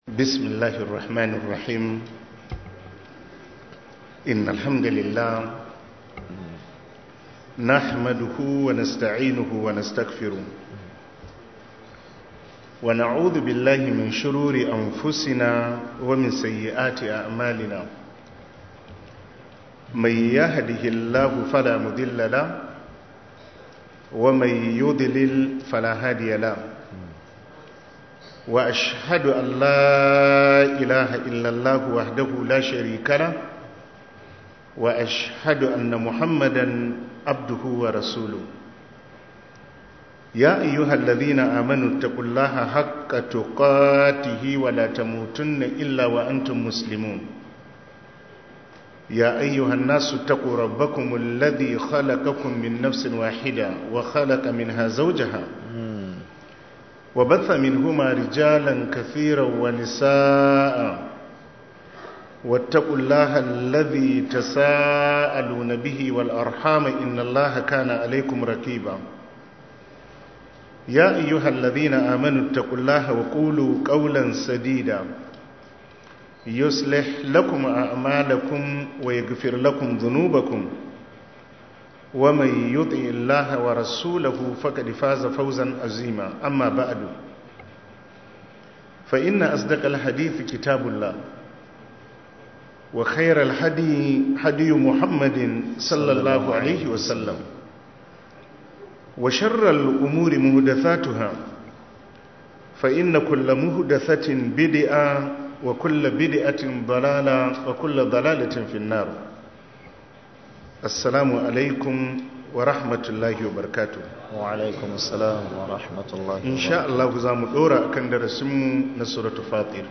← Back to Audio Lectures 13 Ramadan Tafsir Copied!